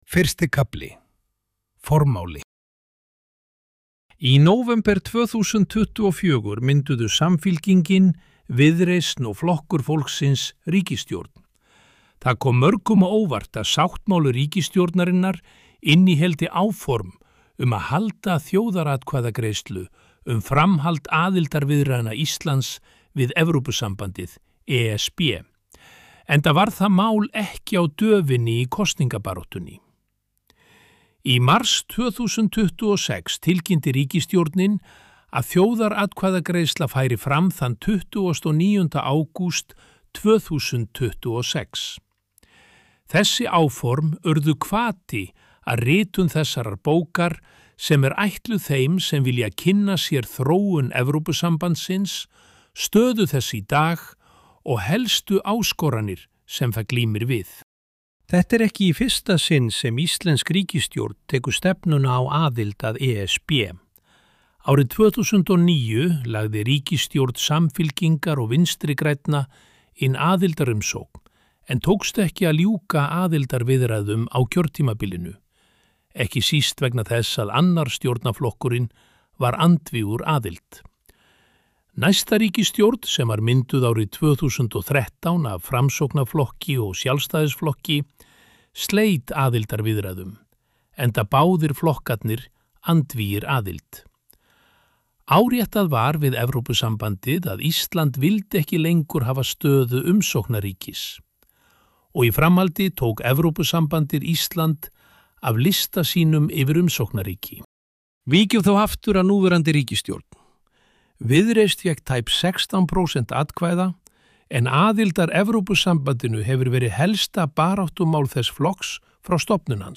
Bókin um ESB – Hljóðbók
Lesari er gervigreindin Davíð sem les frekar hægt og er skýrmæltur. Hljóðbókin er efnislega eins og bókin fyrir utan myndir og neðanmálsgreinar.